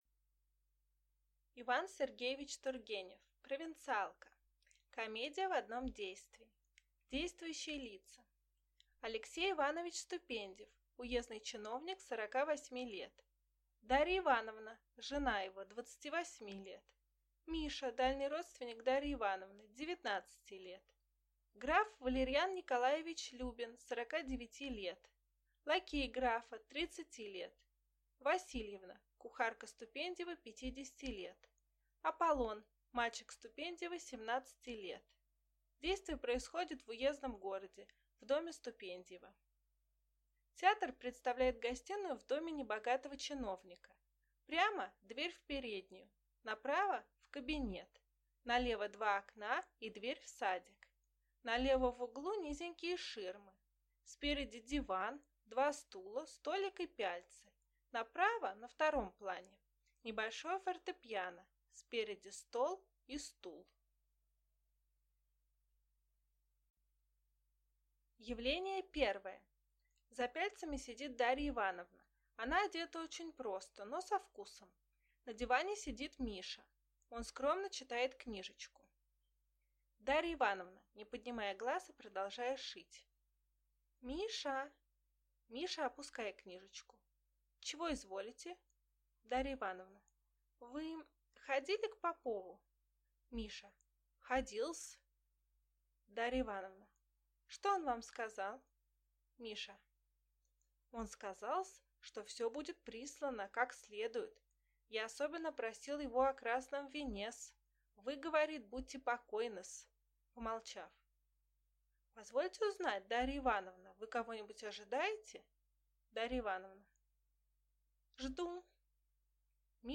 Аудиокнига Провинциалка | Библиотека аудиокниг